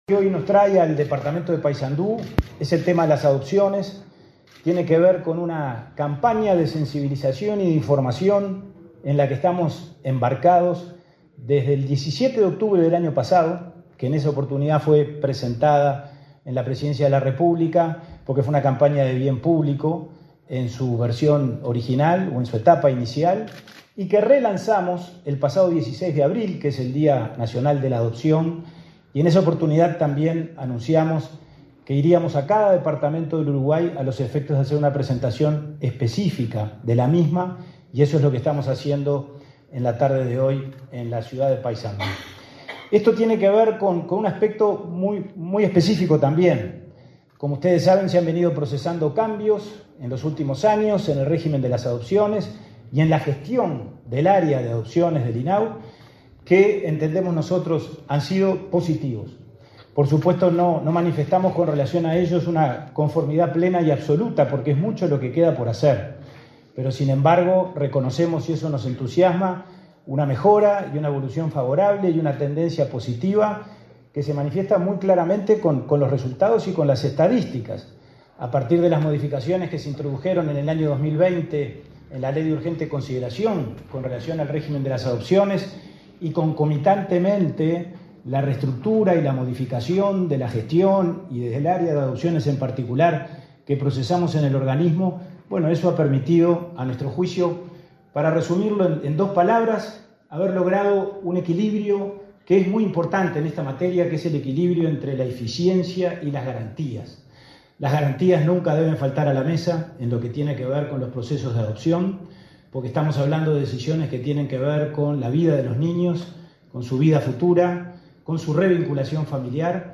Palabras del presidente del INAU, Pablo Abdala